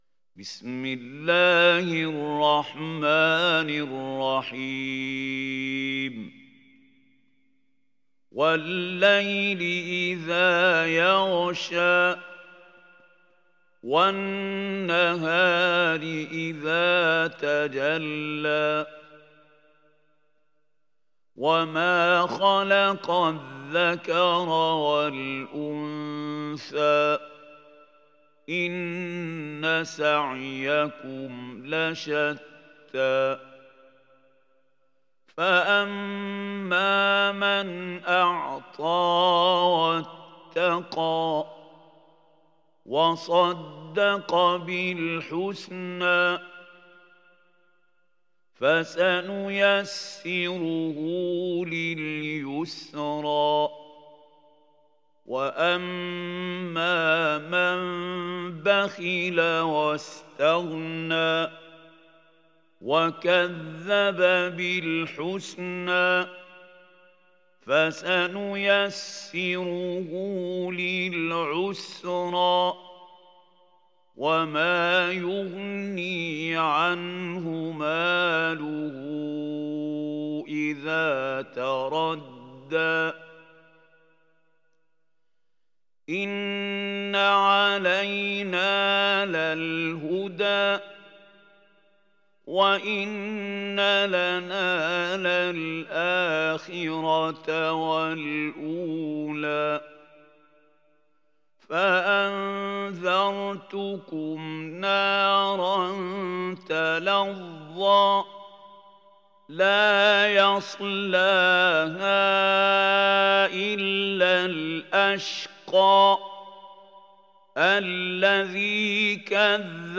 Surah Al Layl Download mp3 Mahmoud Khalil Al Hussary Riwayat Hafs from Asim, Download Quran and listen mp3 full direct links